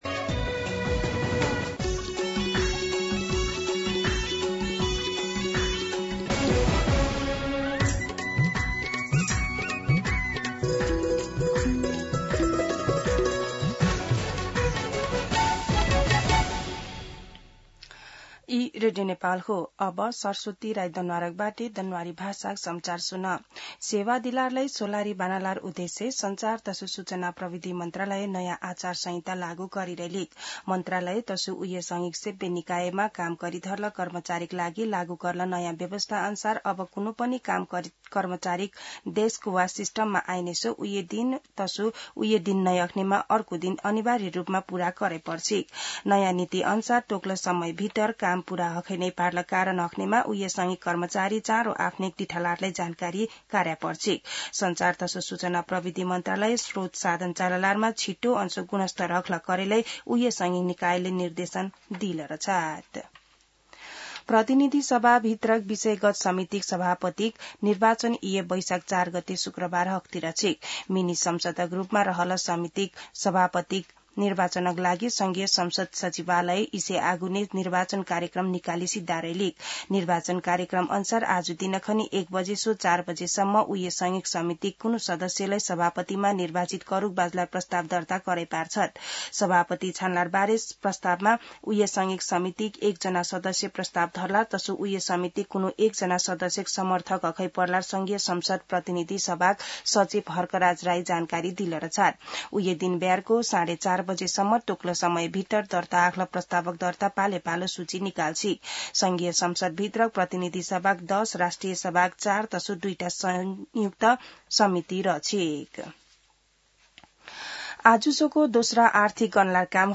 दनुवार भाषामा समाचार : २ वैशाख , २०८३
Danuwar-News-1-2.mp3